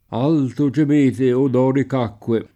dorico [d0riko] etn. stor.; pl. m. -ci — un es. poet. di elis.: alto gemete, O Doric’acque [